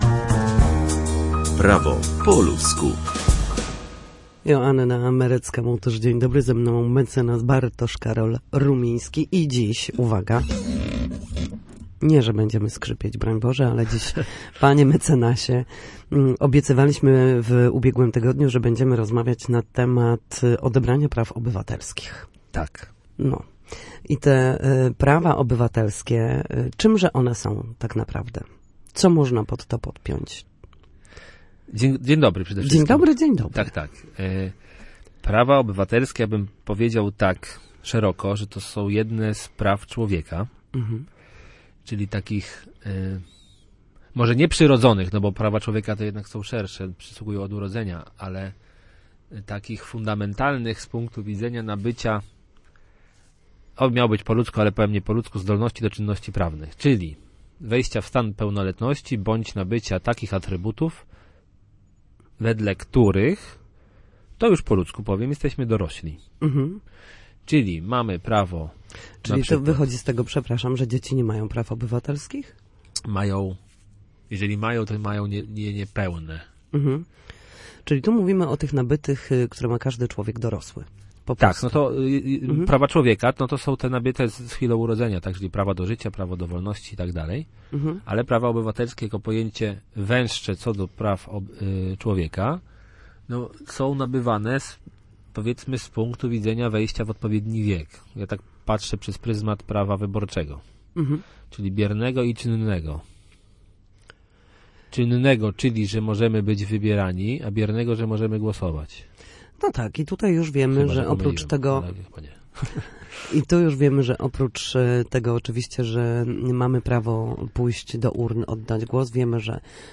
W każdy wtorek o godzinie 13:40 na antenie Studia Słupsk przybliżamy państwu meandry prawa. Nasi goście, prawnicy, odpowiadają na pytania dotyczące zachowania w sądzie czy podstawowych zagadnień prawniczych.